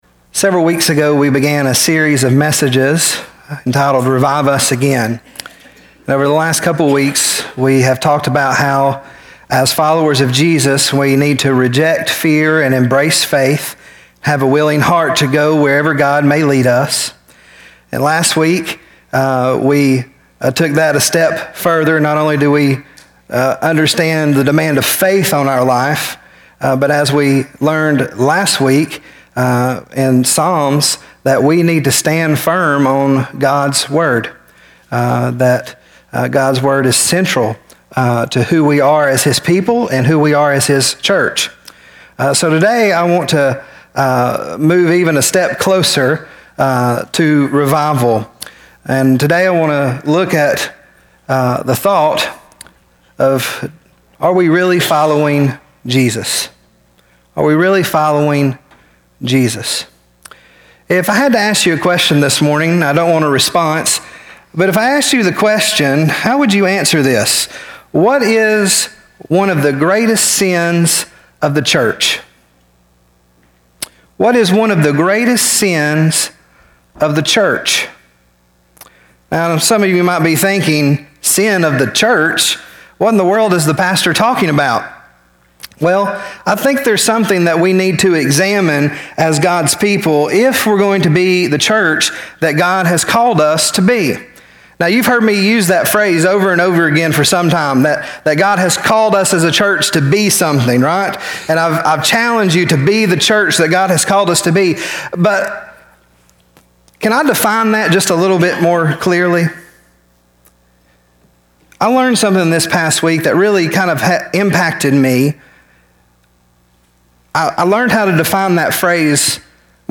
Luke 9:23-27 Service Type: AM Worship Topics